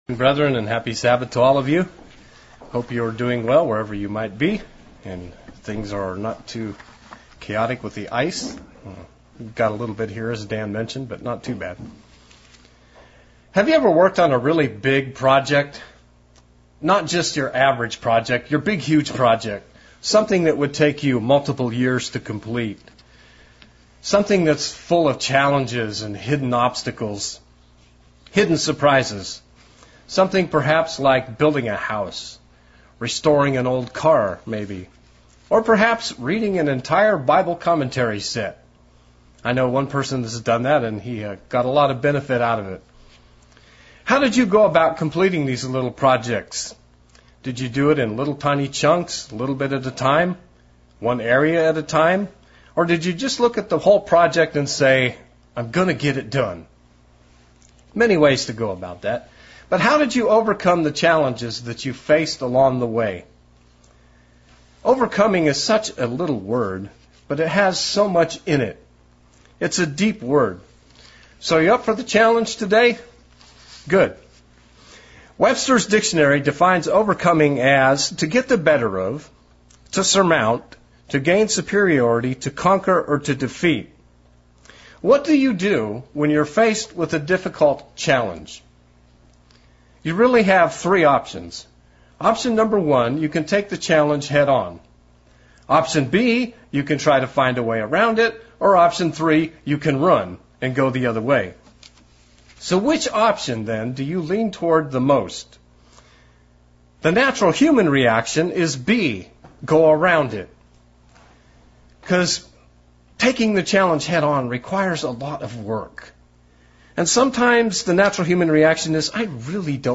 Split sermon looking at the obstacles and challenges that stand in our way as we struggle to overcome them.